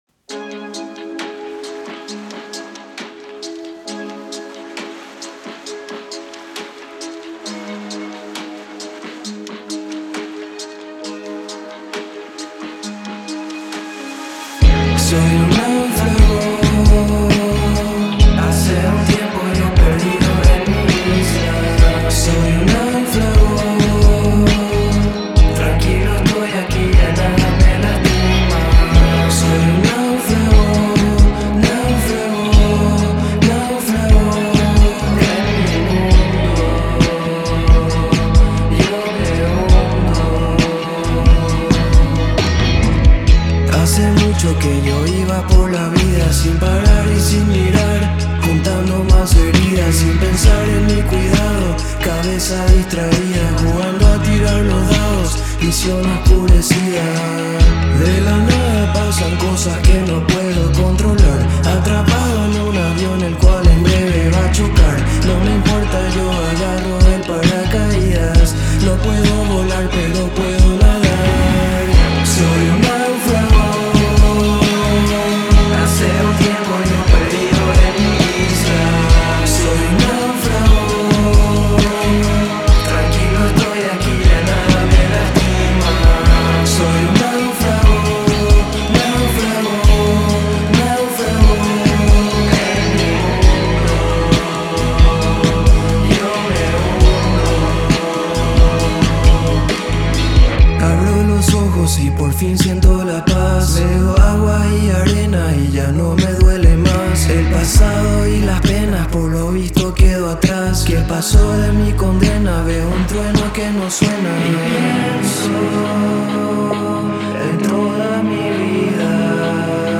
A través de una interpretación cruda y sincera
aportando un contrapunto íntimo y etéreo